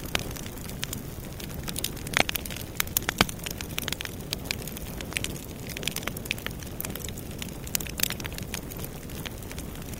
grab_flame1.wav